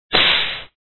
whip.wav